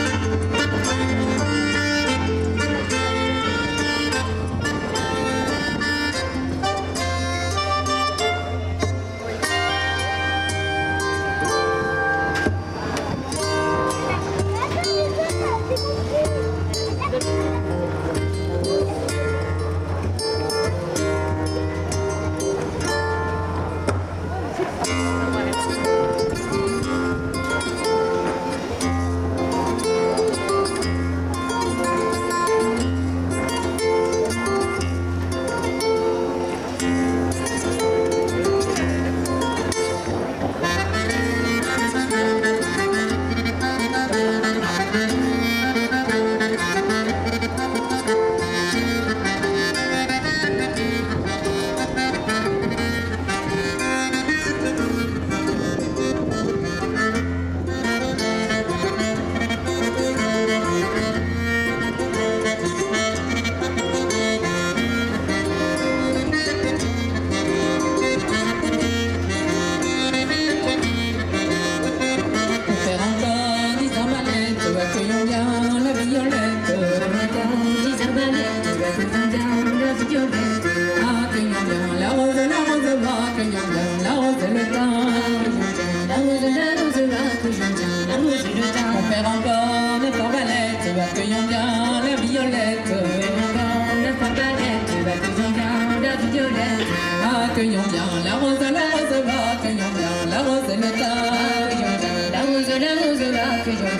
Musique !
et modernité : guitare flamenca et un accordéon avec des accents de Piazzola.
y modernidad: guitarra flamenca y acordeón con acentos a Piazzola.
bretagne_sud-pont-aven_piazzola.mp3